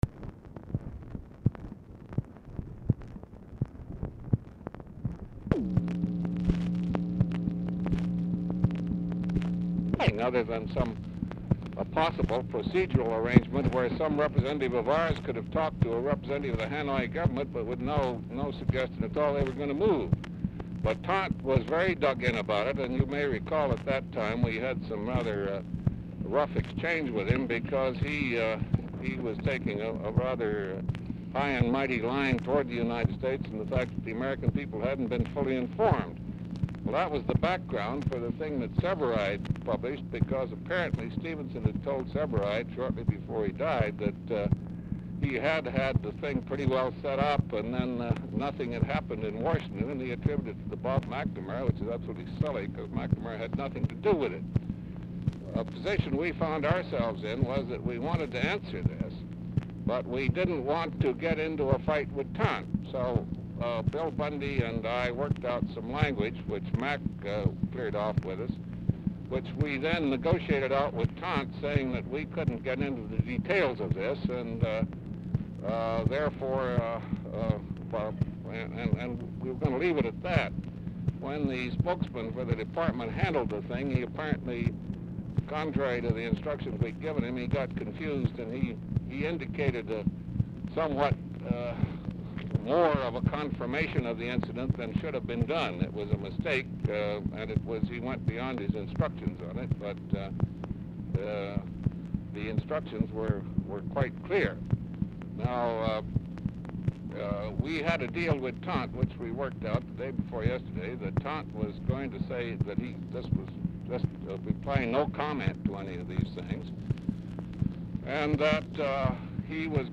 Oval Office or unknown location
RECORDING STARTS AFTER CONVERSATION HAS BEGUN
Telephone conversation
Dictation belt